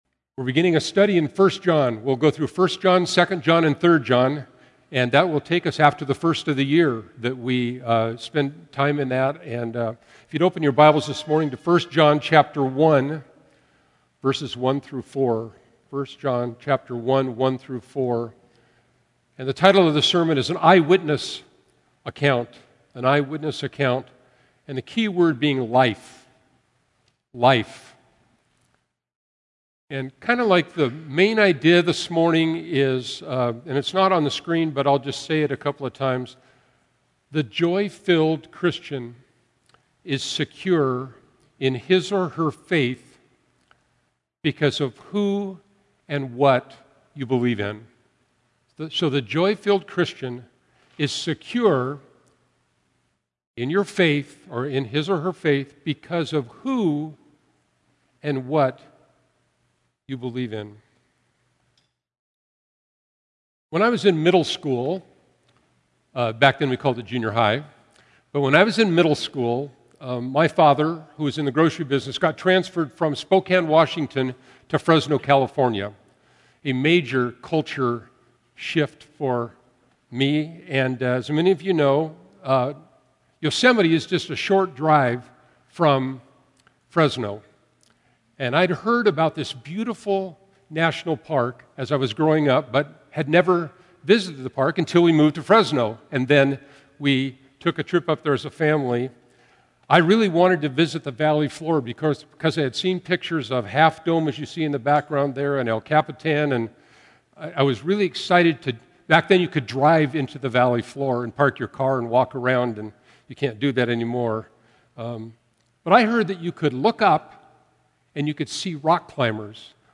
A message from the series "1-3 John."
8-September-Sermon-2024.mp3